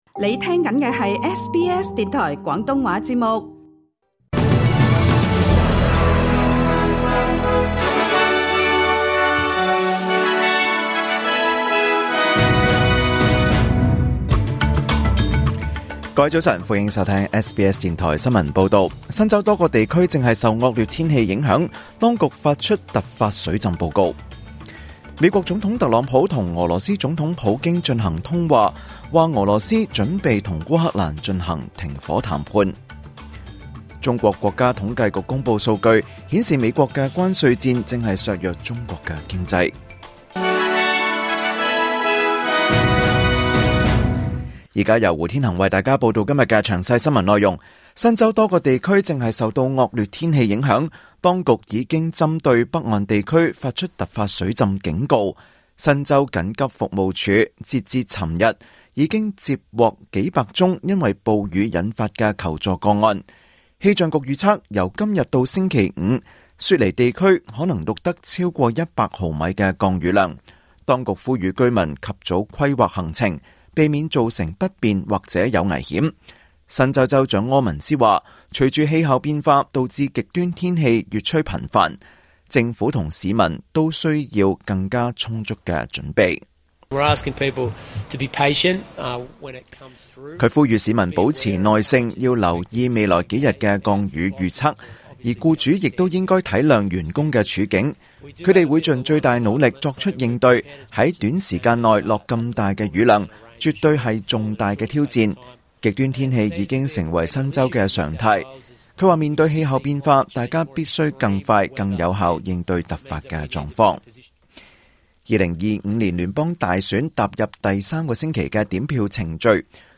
2025年5月20日 SBS 廣東話節目九點半新聞報道。